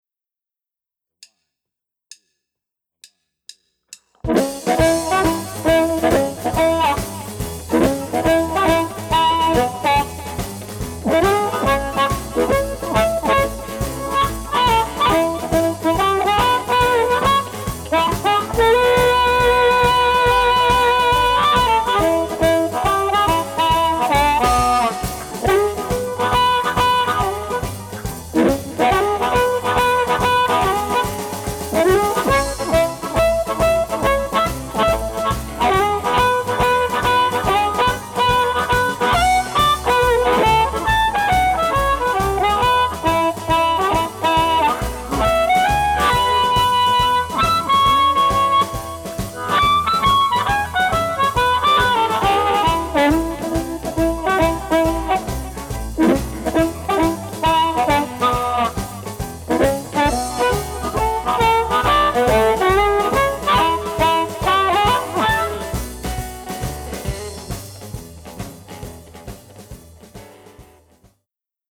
Amp Reviews | Blues Harmonica
Download the review sheet for each amp and the recorded mp3 files to compare the amps to make your choice of favorite amp.